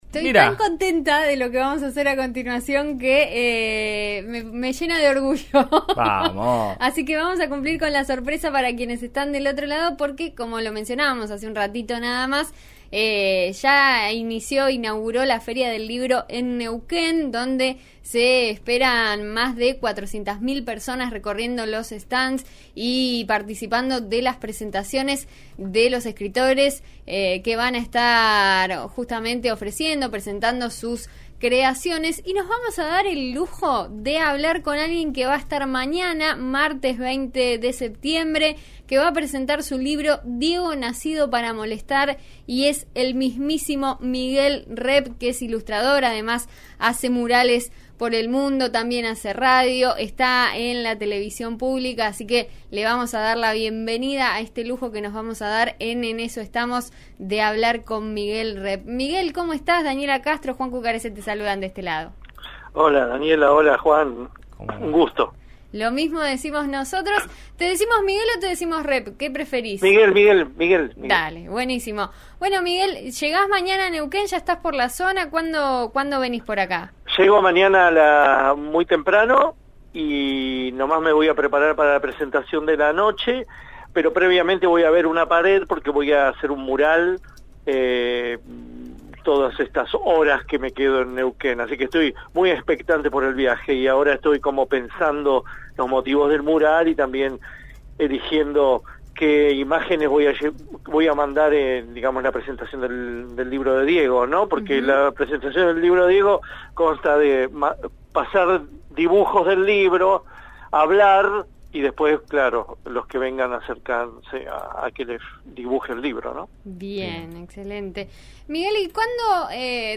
El surgimiento de este homenaje a Maradona y por qué se considera maradoneano, aun cuando no lo era. La nota con En Eso Estamos, de RN Radio, acá.